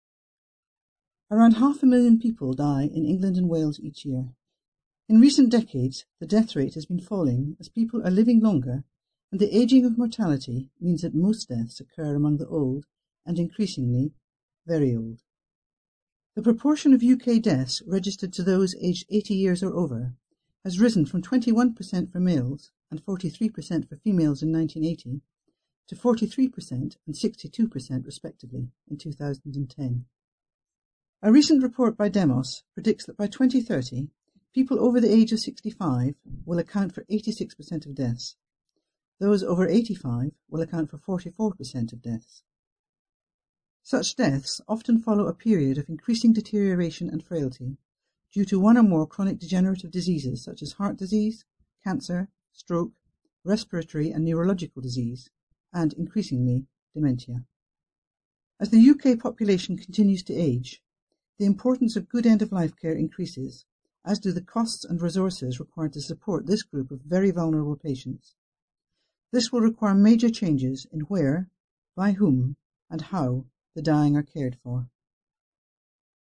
Narration audio